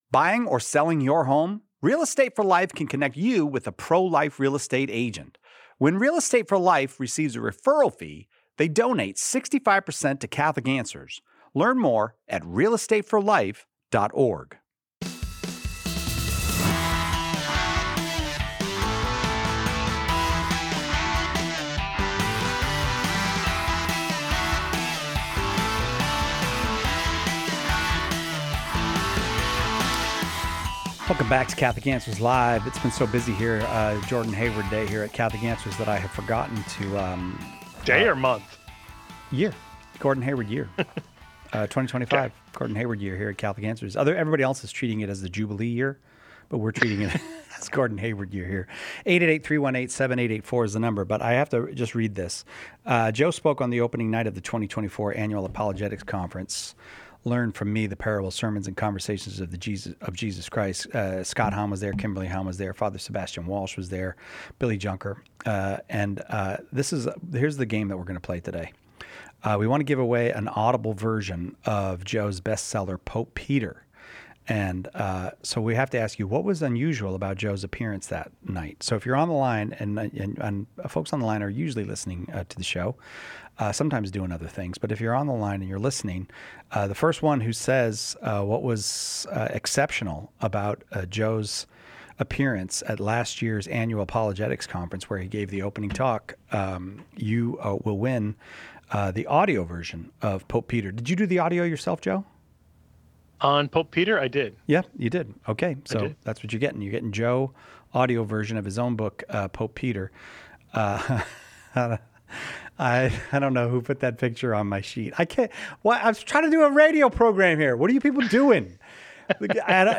A caller shares that his coworker refuses to take direction from a female supervisor, citing Scripture as justification. In this episode, Catholic apologists respond with clarity on what the Church teaches about gender roles, authority, and human dignity.